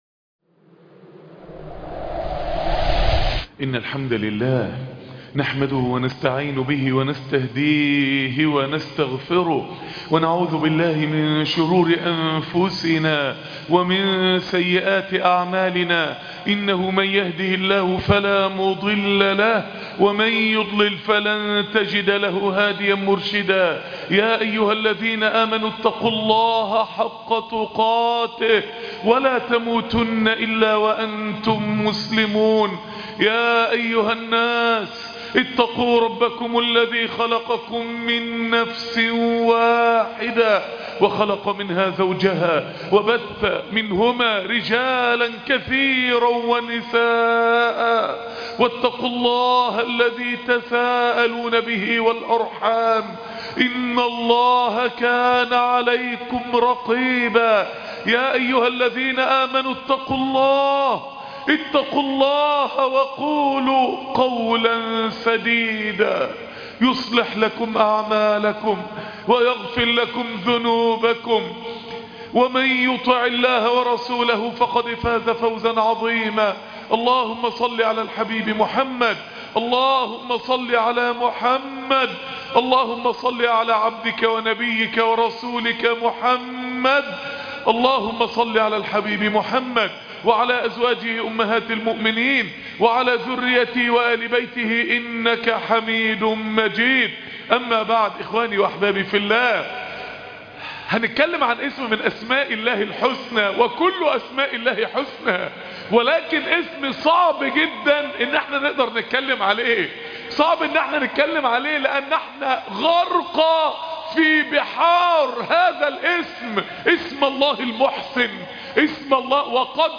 المعاني الثمانية لاسم الله المحسن - .. خطبة جمعة ..